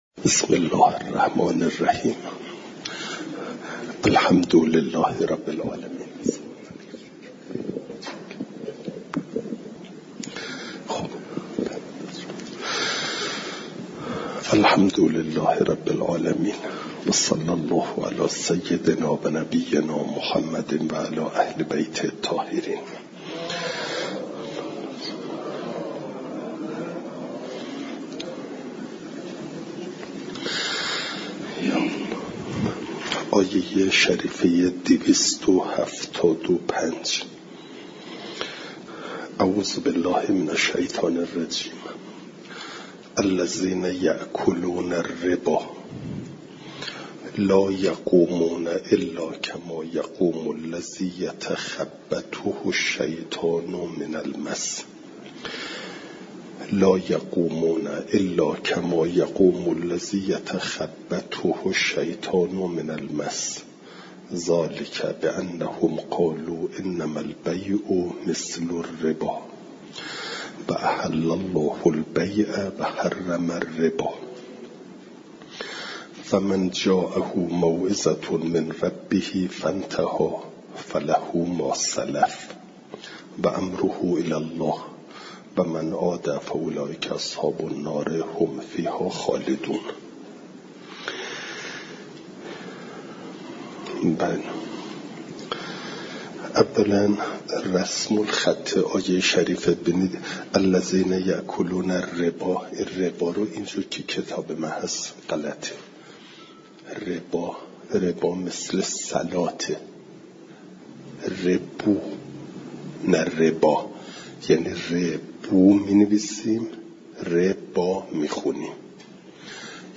درس تفسیر مجمع البیان